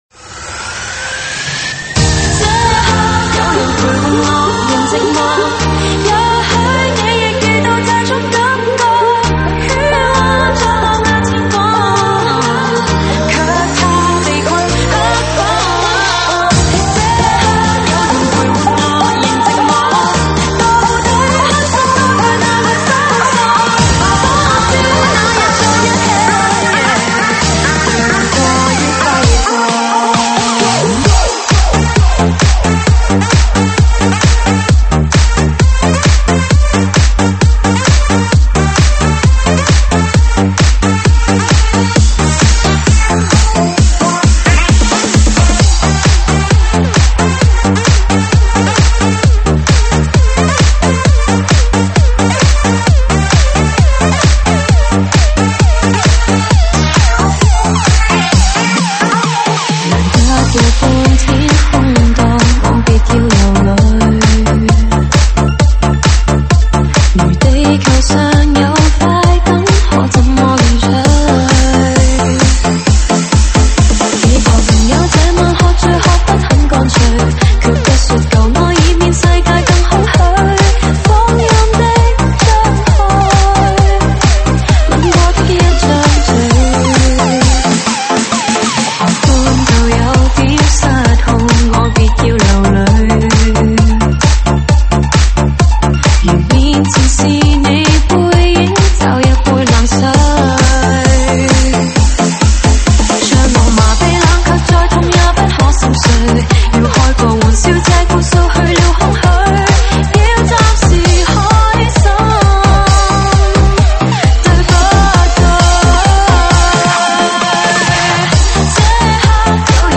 收录于(现场串烧)提供在线试听及mp3下载。